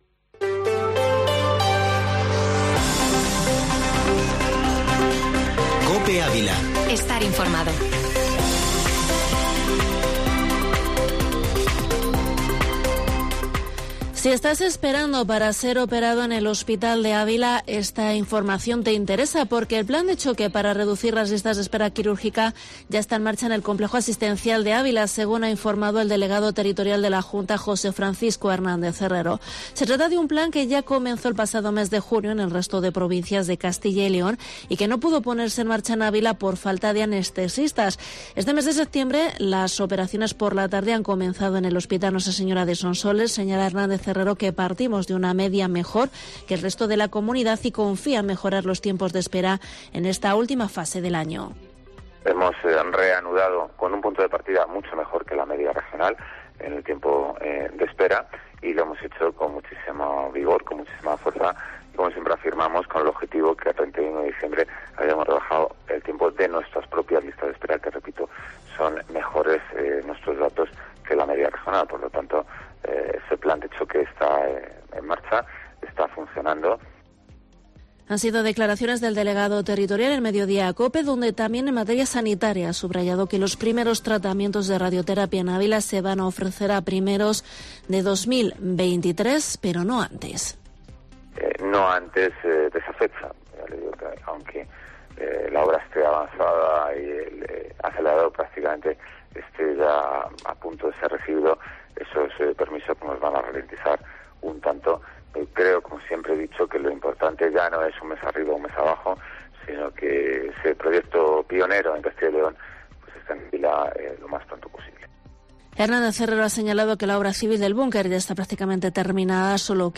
Informativo Mediodía COPE en Ávila 12/9/22